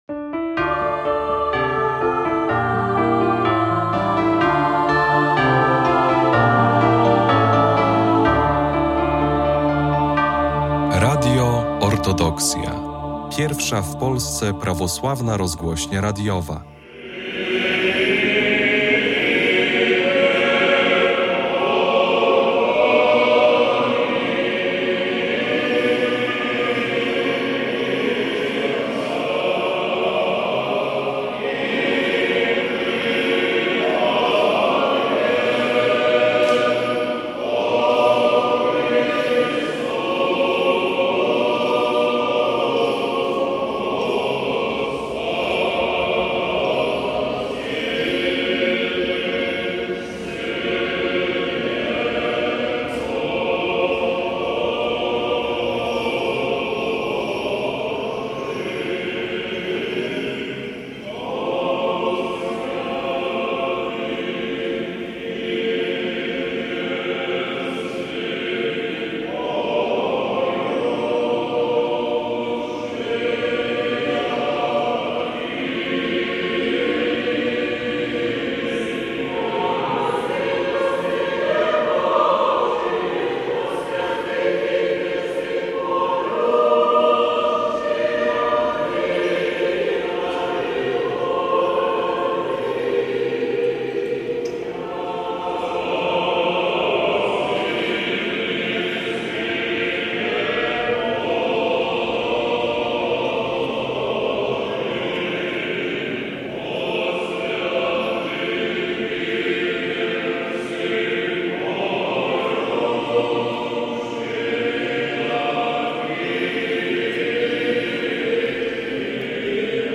4 sierpnia 2025 roku w Warszawie odbyły się modlitewne uroczystości ku czci św. Marii Magdaleny – patronki katedry metropolitalnej, będącej głównym ośrodkiem życia liturgicznego prawosławnej Warszawy i centralną świątynią Polskiego Autokefalicznego Kościoła Prawosławnego.